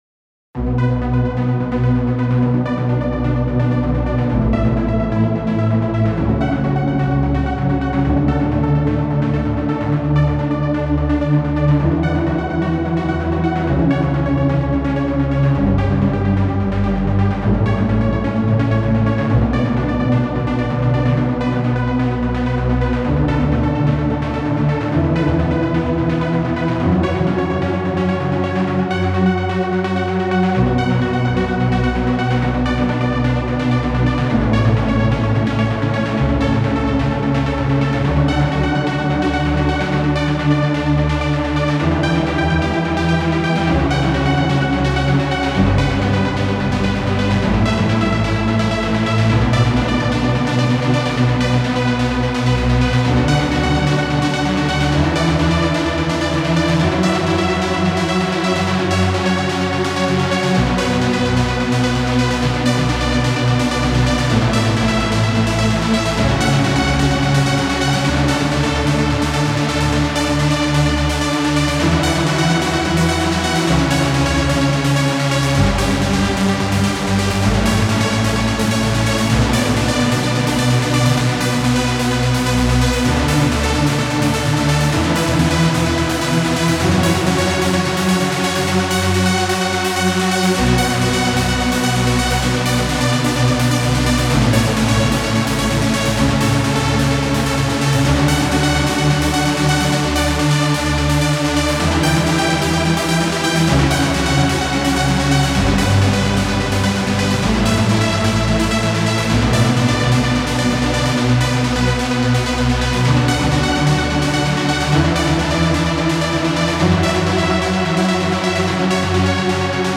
Resplandor arpegiado publicado el 17 de agosto de 2025.